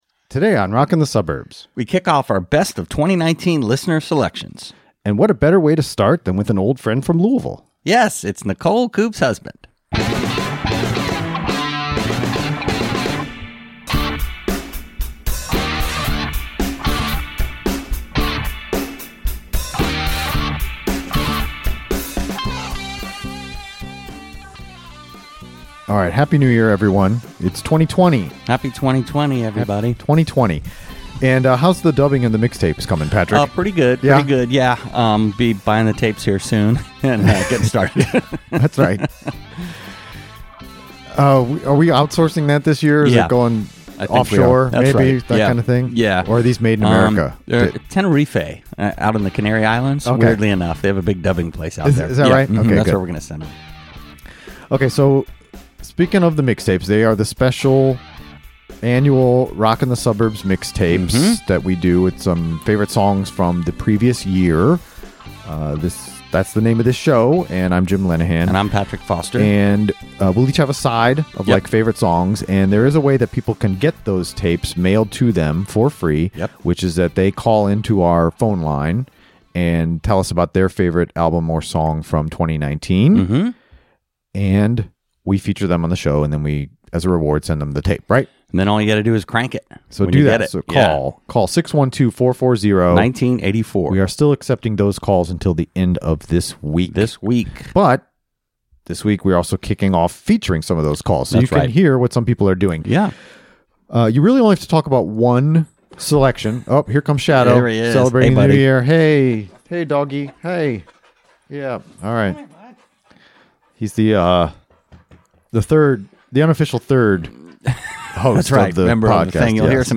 calls in to tell us about his faves of 2019